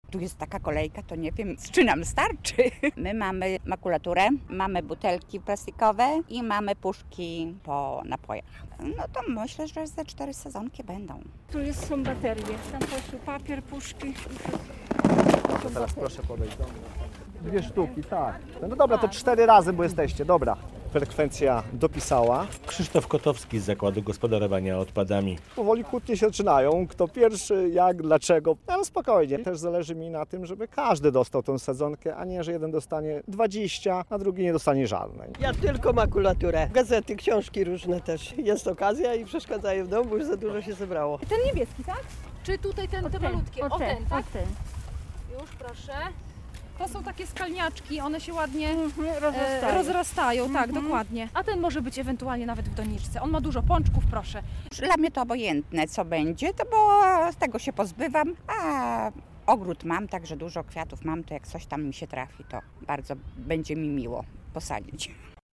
Akcja "Ekologia za sadzonkę" - relacja